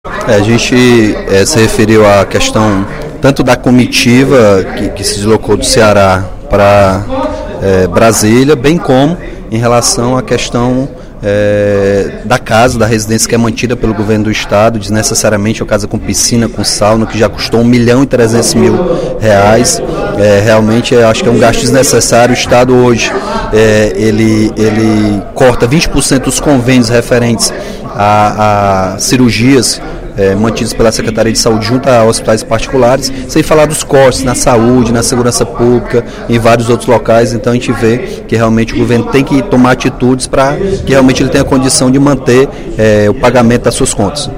No primeiro expediente da sessão plenária desta quinta-feira (26/03), o deputado Capitão Wagner (PR) comentou a polêmica envolvendo a saída de Cid Gomes do Ministério da Educação.